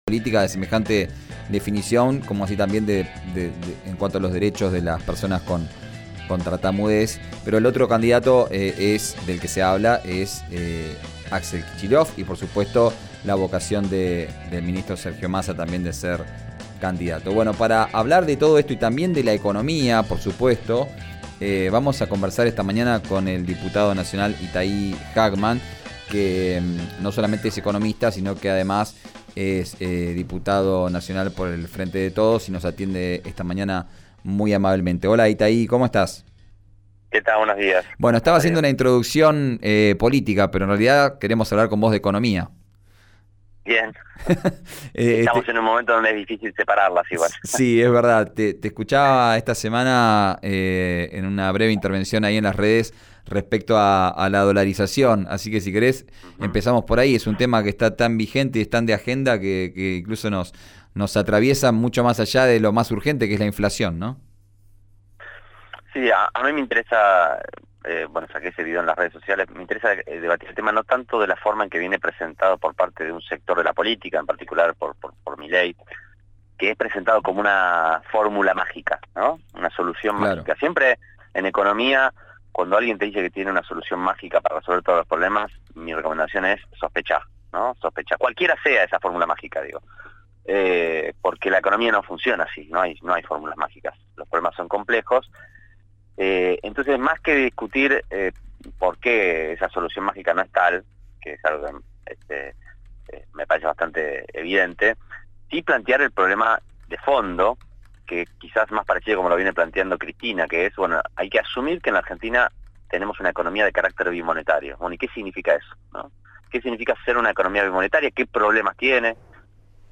El diputado nacional del Frente de Todos, Itaí Hagman, dialogó con RÍO NEGRO RADIO. Escuchá la entrevista completa.